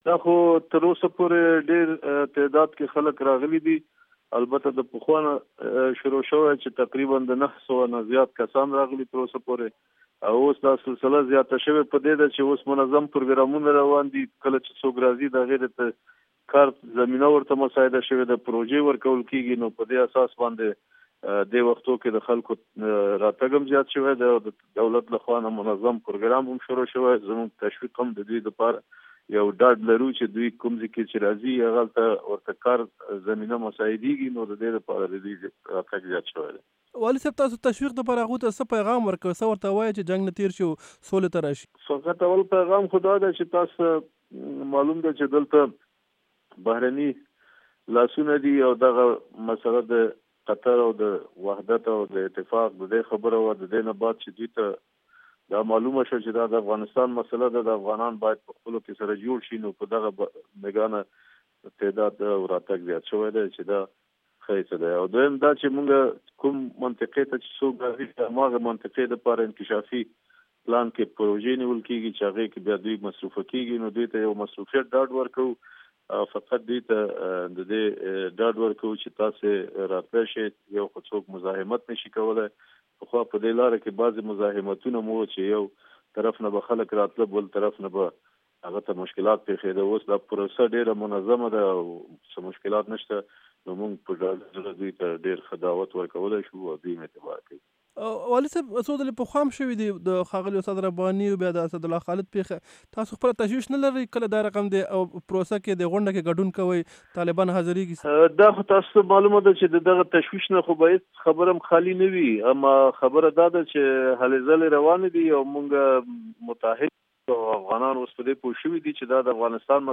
governor kunar interview